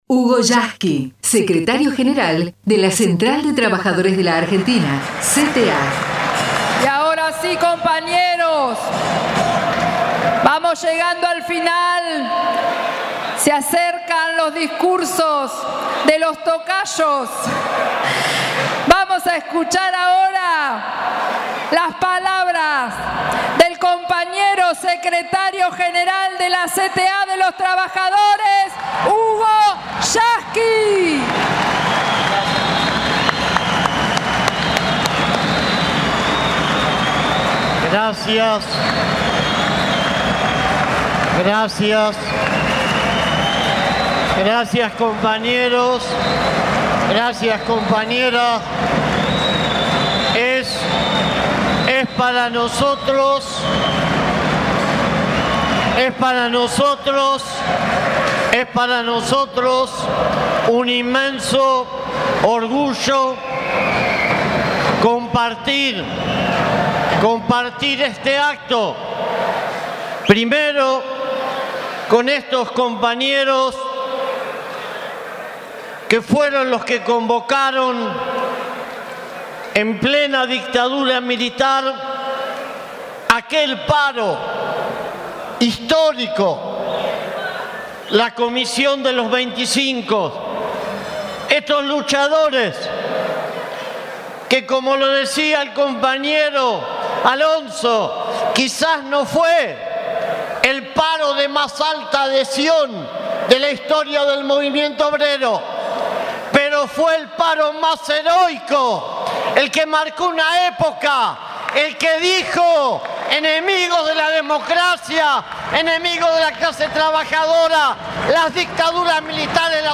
ACTO EN FERRO / HUGO YASKY
hugo_yasky_acto_en_ferro_completo.mp3